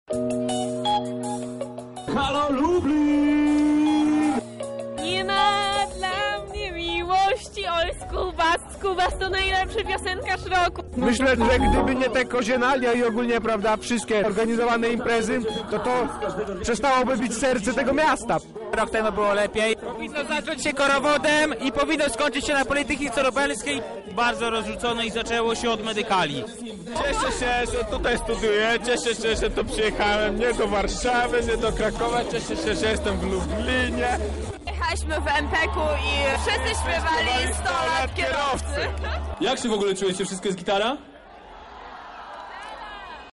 Kiedy tłumy śpiewały razem ze swoimi ulubionymi zespołami , słychać było, że to studenci rządzą Lublinem.